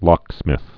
(lŏksmĭth)